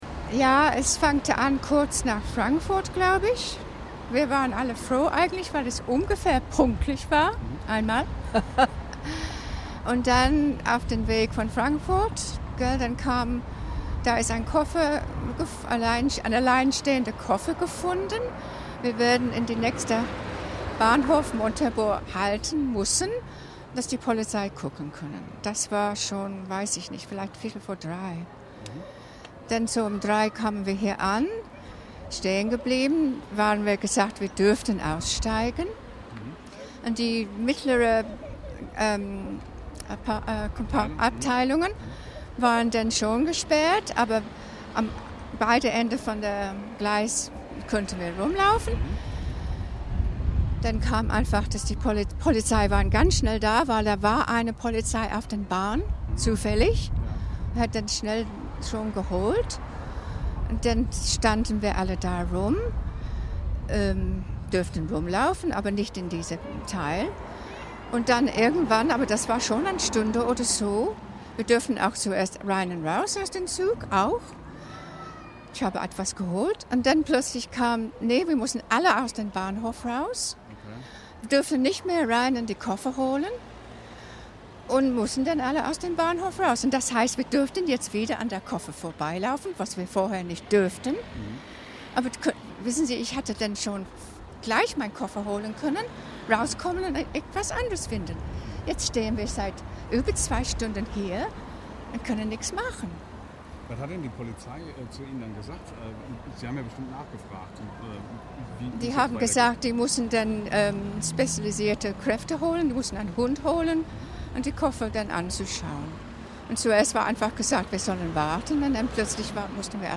Downloads MP3: Diese Frau hat die Sperrung am Montabaurer Bahnhof erlebt Artikel teilen Artikel teilen Ressort und Schlagwörter Westerwälder Zeitung